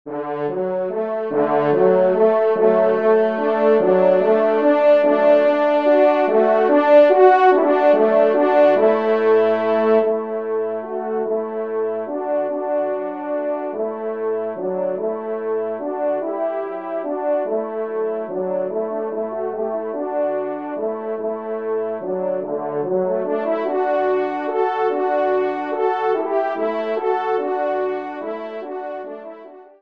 Genre :  Divertissement pour Trompes ou Cors en Ré
1e Trompe             2e Trompe